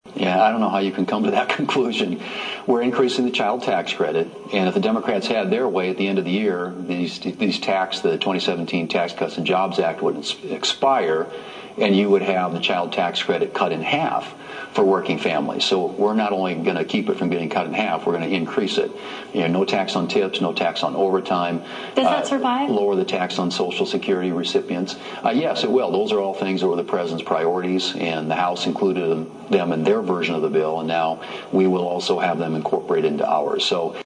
WASHINGTON, D.C.(HubCityRadio)- Fox News Sunday’s host Shannon Breem did a pre-recorded interview with U.S. Senate Majority Leader John Thune which aired on Sunday.